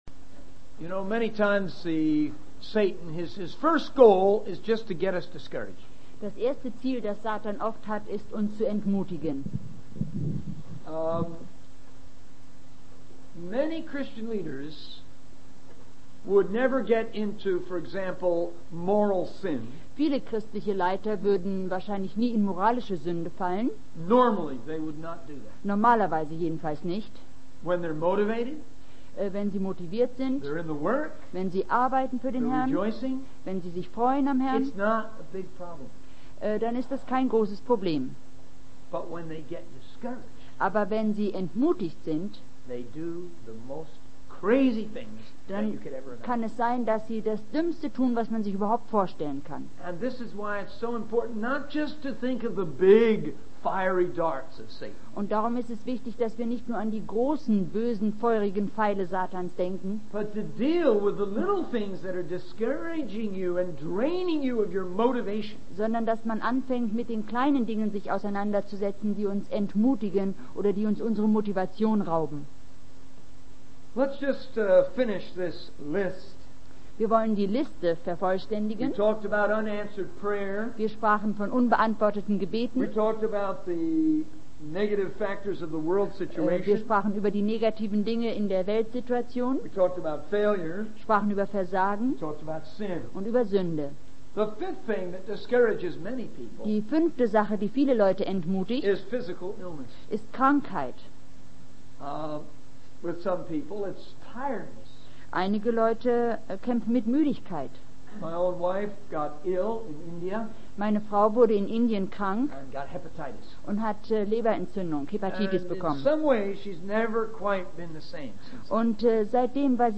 In this sermon, the speaker discusses the importance of survival and relaxation in Christian work. He shares his personal experience of realizing the need to pull back and focus on small tasks instead of constantly striving for big victories. The speaker emphasizes the value of taking time to gather one's thoughts and talk to Jesus, rather than feeling compelled to evangelize every moment.